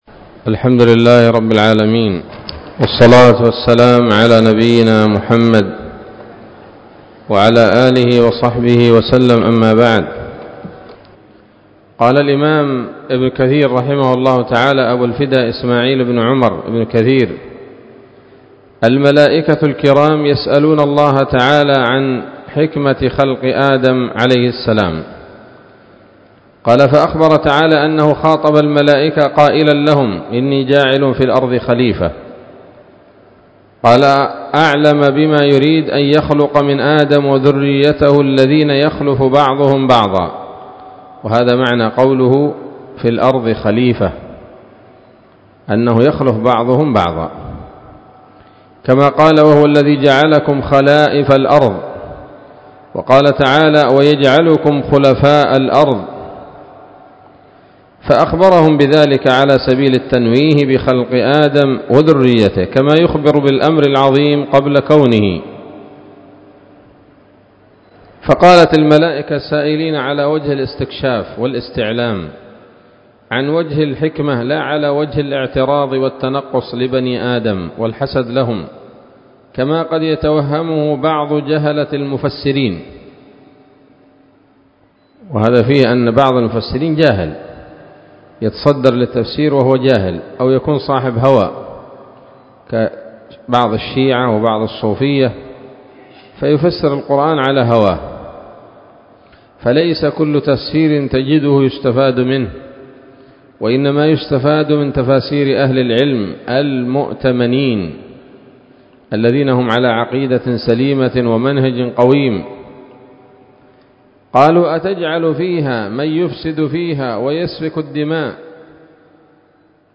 الدرس الثاني من قصص الأنبياء لابن كثير رحمه الله تعالى